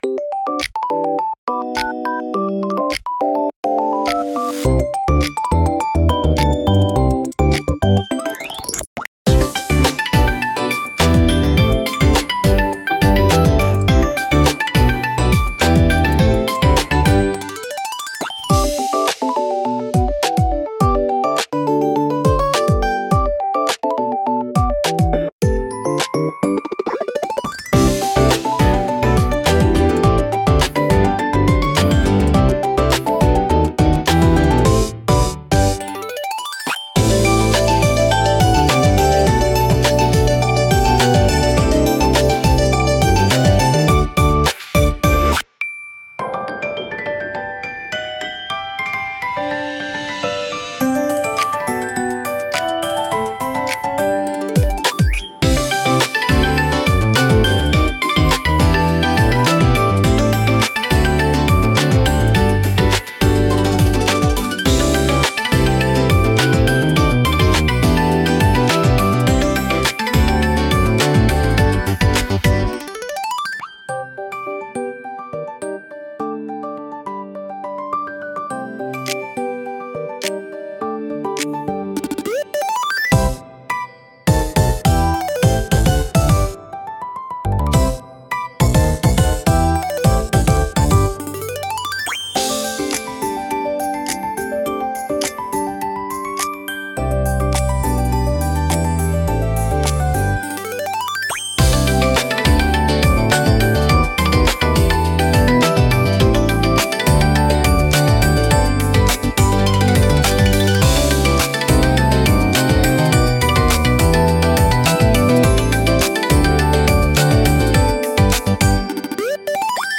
キラキラ駆け抜ける、ゆめかわポップBGM